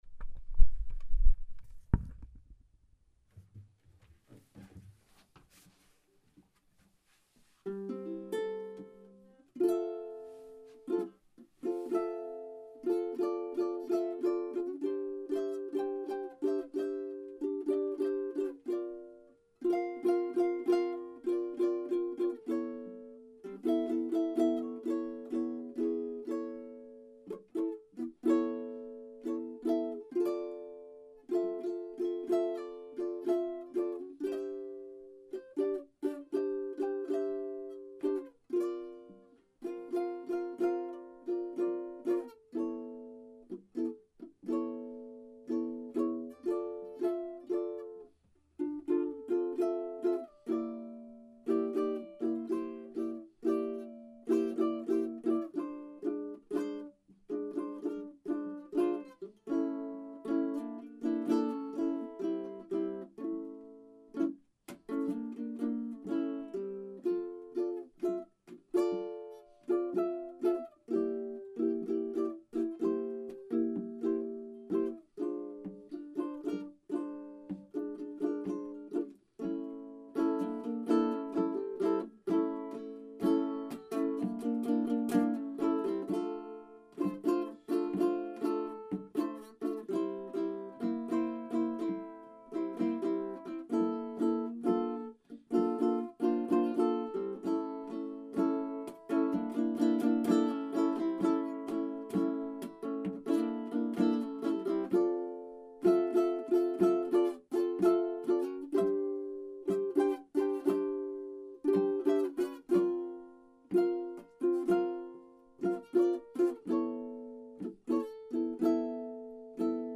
je me découvre avec lui des envies de jouer du jazz à l'ukulélé et de découvrir des accords enrichis, et d'autres accords ouverts, avec des cordes à vides.
La prise de son a été faite dans mes wc, je trouve que l'acoustique y est pas mal (je suis d'ailleurs en train de me demander si je vais pas essayer des transporter mon beau micro ici de tremps en temps pour prendre des voix " aériennes"....). Ici la prise est faite par mon H4n
donc, c'est là  rien de très intéressant musicalement, c'est surtout pour entendre le son de la bête
ma foi.. joli son joué unplugged !
Non pas de médiator. Au début, je joue avec le gras du pouce, ensuite avec l'index et enfin avec le côte du pouce joué comme un médiator.
En low G
ben comme je l'ai dit plus haut : un très joli son, bien typé
kala-jazz.mp3